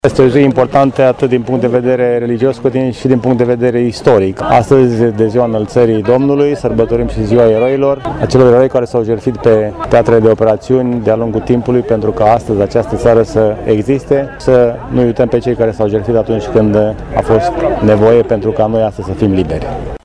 Ceremonialul central s-a desfășurat într-un loc tradițional, la Cimitirul Eroilor Români Sprenghi, unde au fost rostite alocuţiuni referitoare la însemnătatea Zilei Eroilor.
La rândul său, primarul Brașovului, George Scripcaru, a spus: